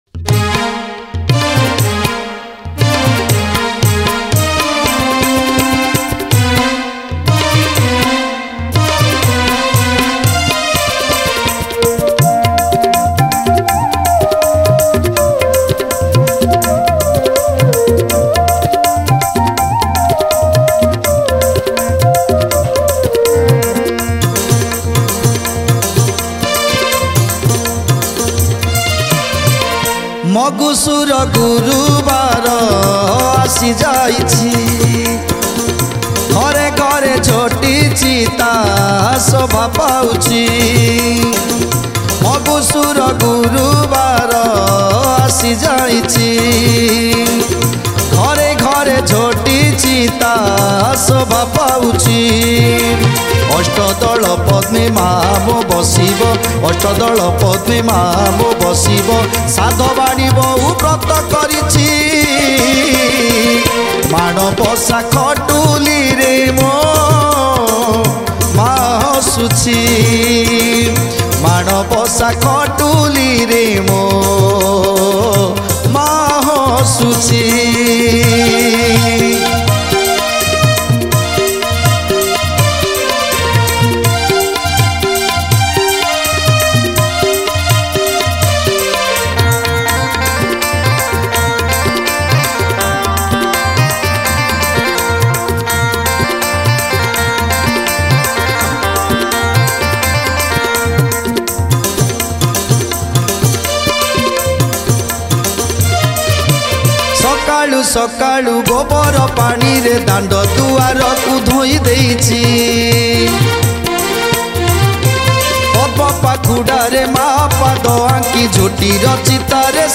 Manabasa Gurubara Bhajan Songs Download